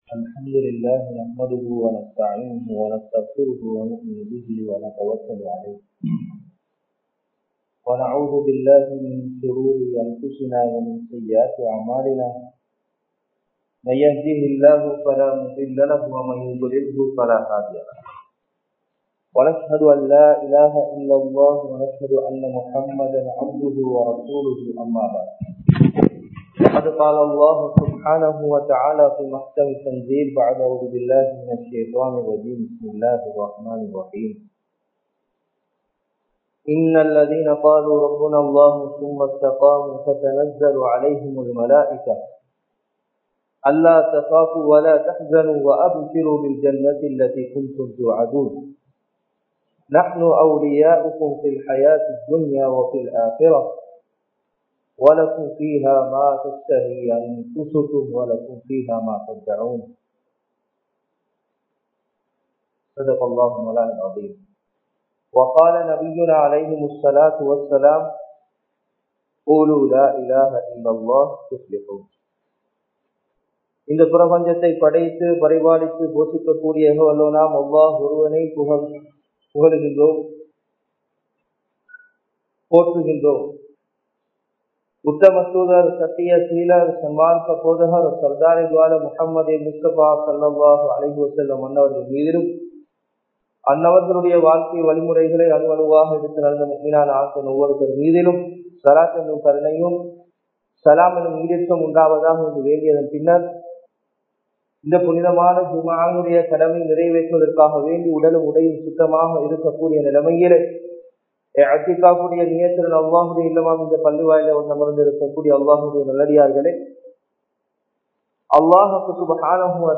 இஸ்லாம் கூறும் நீதம் | Audio Bayans | All Ceylon Muslim Youth Community | Addalaichenai
Akurana, Dalgastana, Habeeb Jumua Masjid 2020-12-25 Tamil Download